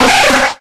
Cries
NIDORANfE.ogg